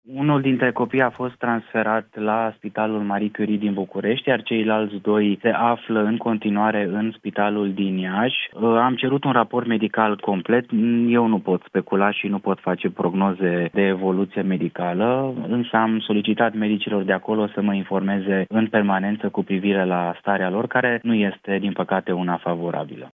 „Au gestionat cu nepăsare! Cel mai probabil, vor fi dați afară!”, a declarat la Europa FM ministrul Sănătății, Alexandru Rogobete, despre conducerea Spitalului de Copii „Sfânta Maria” din Iași, după ce șase copii au murit la secția ATI, infectați cu bacteria Serratia marcescens.